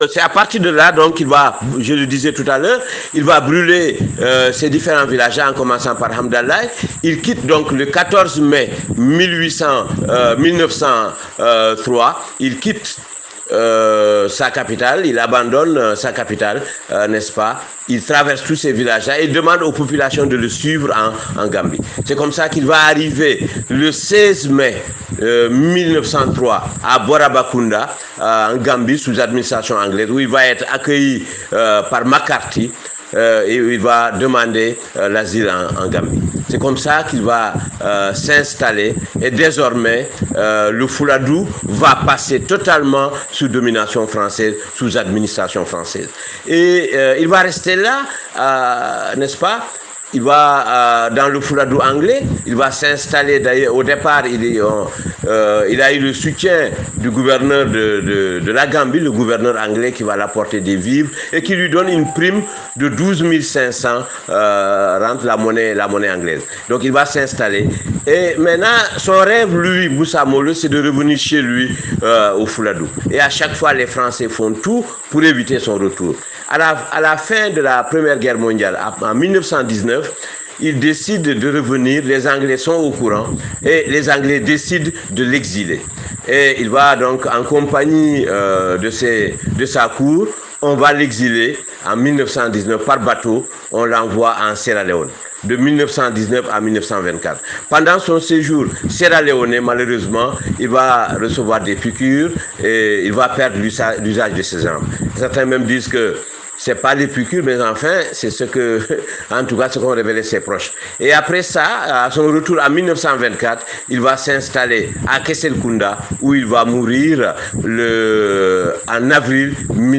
Ici quelques audios de l’exposé